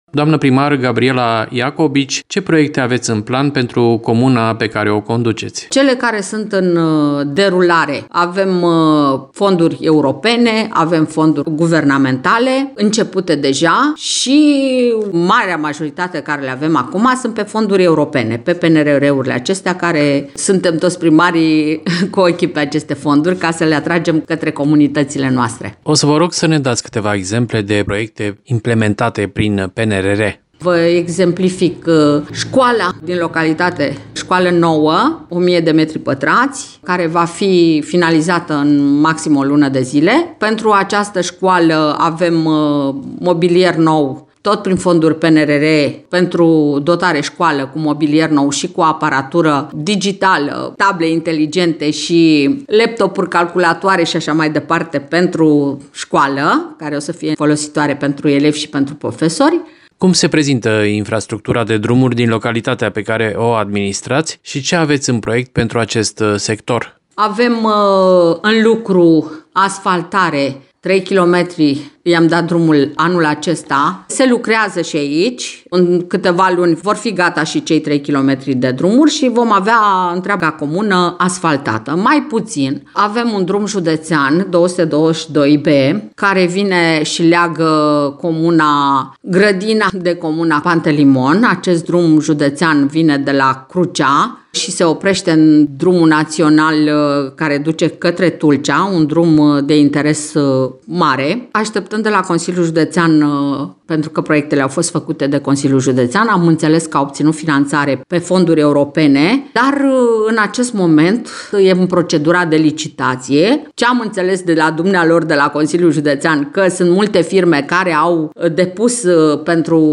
din discuția cu primarul Gabriela Iacobici.